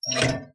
Vault_Open.wav